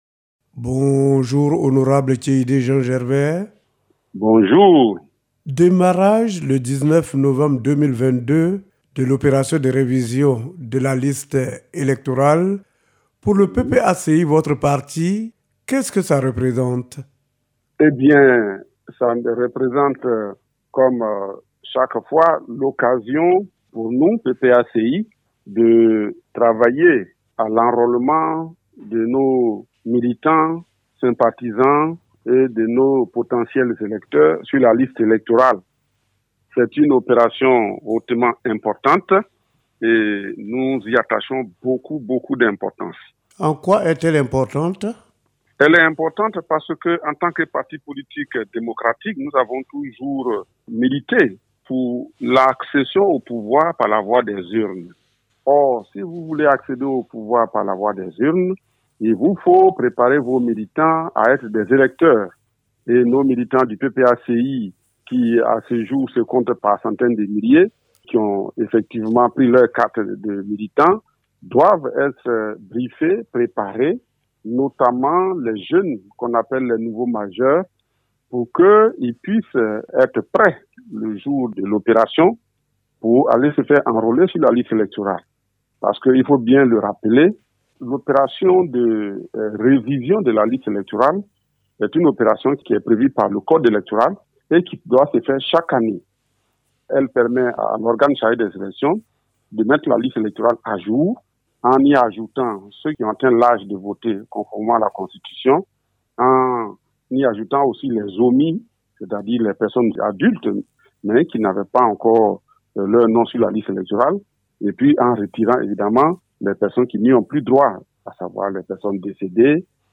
Député de la Nation élu dans la circonscription de Bloléquin dans l’Ouest de la Cote d’Ivoire, Vice Président du Parti des Peuples Africains Cote d’Ivoire (PPA-CI), Tchéidé Jean Gervais est aujourd’hui l’Invité de la Rédaction de la Radio de la Paix.
c’est un entretien